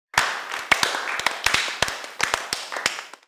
applause-c.wav